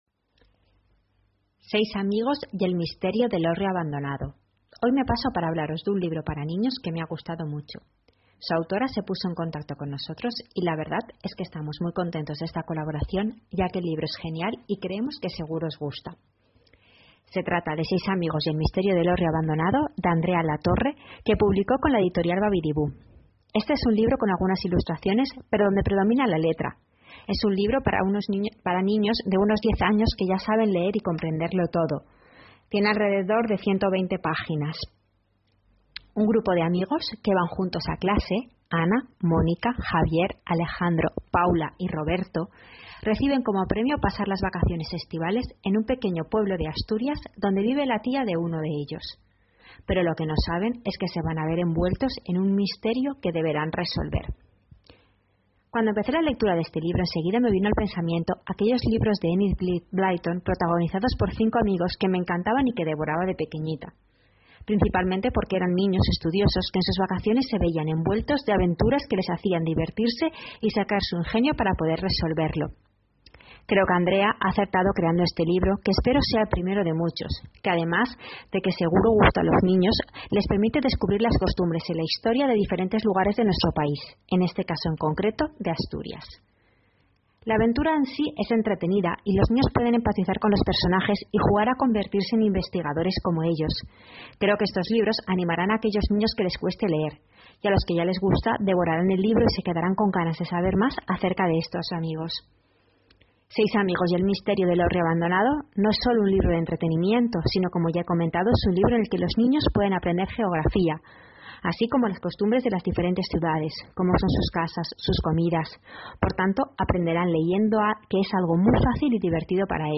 Audio reseña: Seis amigos y el misterio del hórreo abandonado